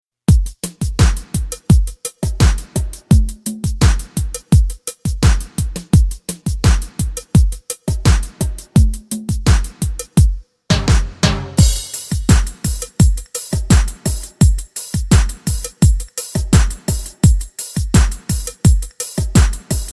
MP3 Demo & Rap
MP3 Demo Instrumental Version